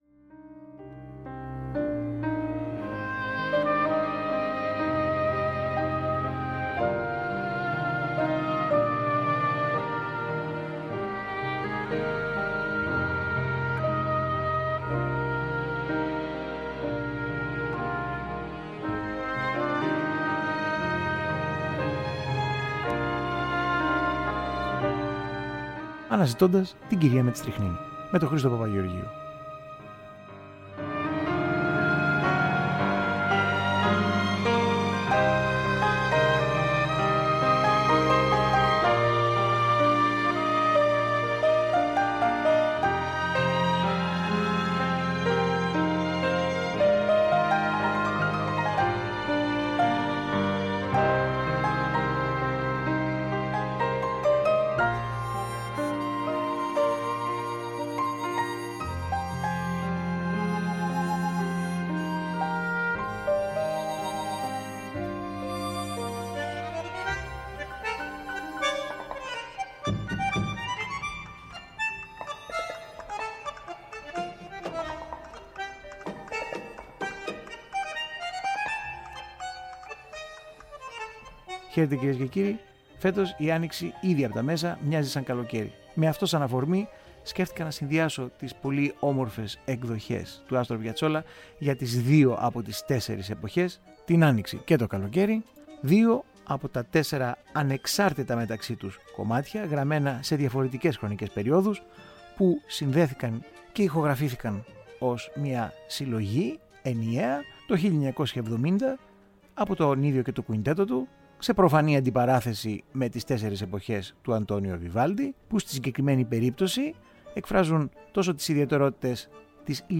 Τα δύο από τα τέσσερα μέρη των «Εποχών» του διάσημου Αργεντίνου, μέσα από μια ποικιλόμορφη παράθεση εκδοχών και ερμηνειών.